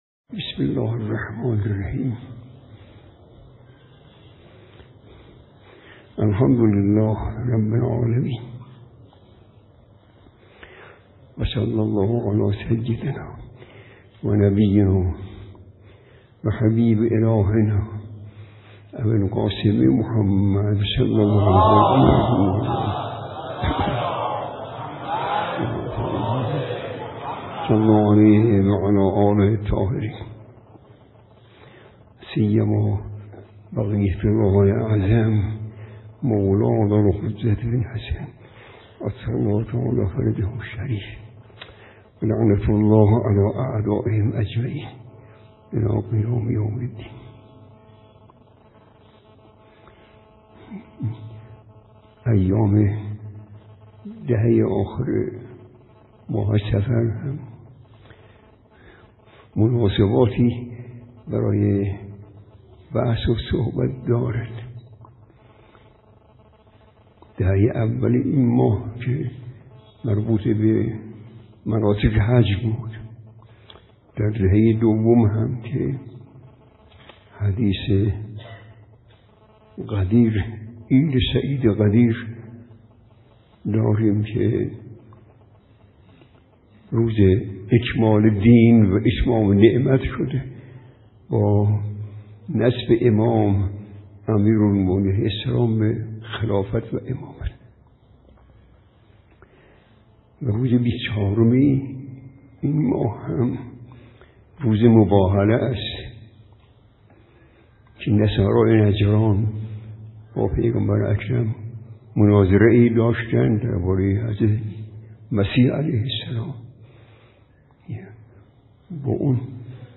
عاشورا عمل عرفه است/ روضه‌خوانی در جلسه تفسیر